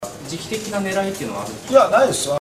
だが、この二人の言葉を逆再生してみると、裏では、記者は【なぁ〜んで、いきなり】という 率直な疑問をリバース・スピーチとして発し、橋下市長は、意外なことに、【そりゃ、ある】 と全く逆の本音をリバース・スピーチとして発していたのだ。(音声では橋本市長の声が先、記者が後)